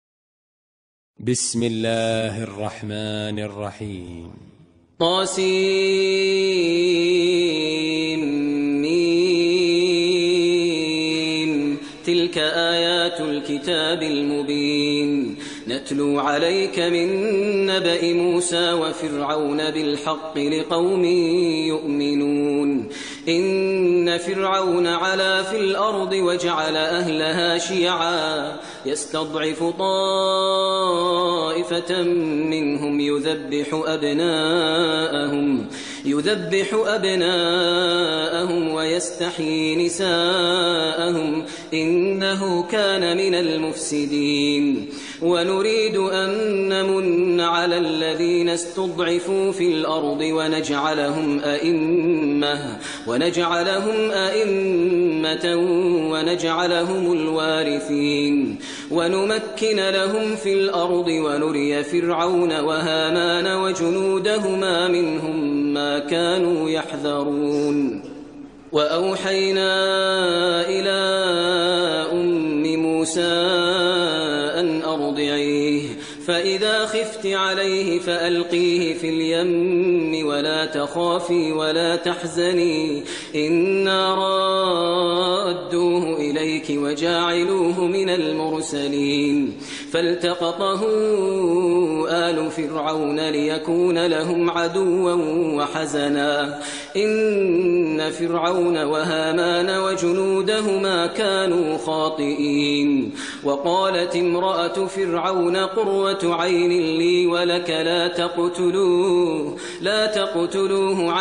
ترتیل سوره قصص با صدای ماهر المعیقلی
028-Maher-Al-Muaiqly-Surah-Al-Qasas.mp3